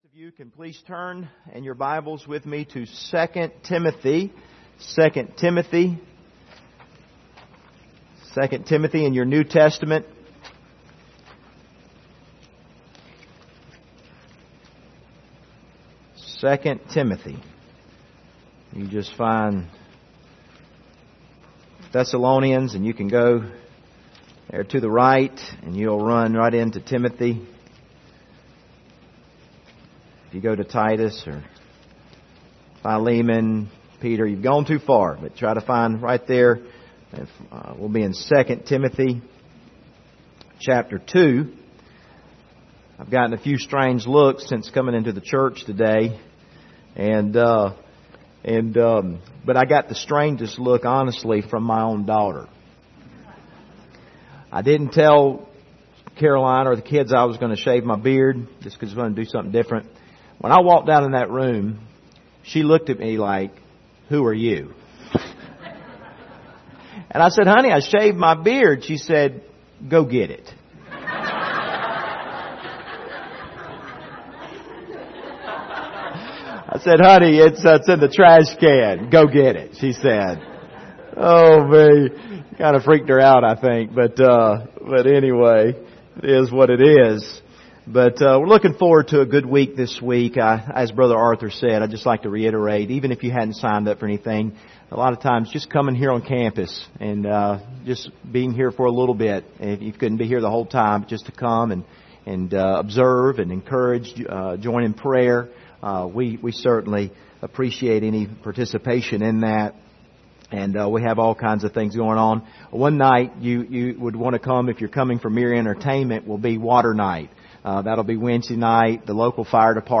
2 Timothy 2:1-5 Service Type: Sunday Morning Download Files Bulletin « Jehovah Ra’ah